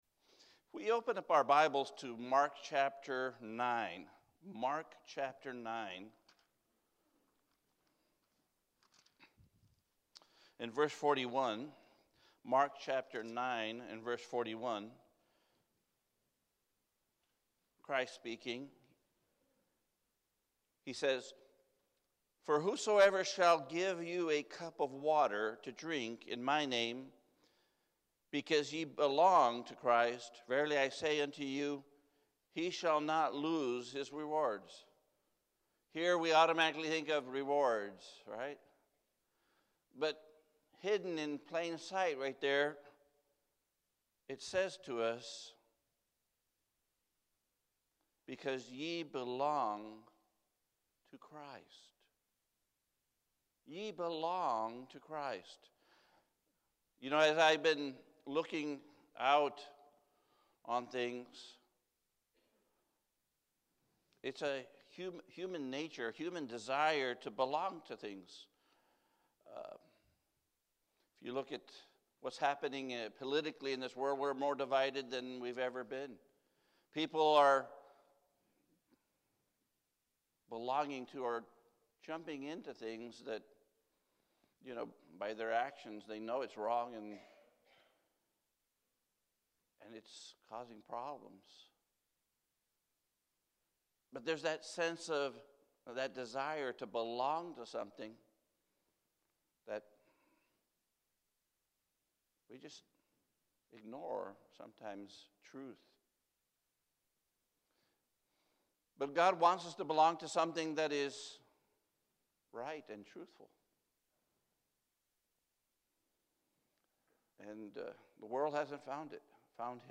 Matthew 12:38-45 Sunday Morning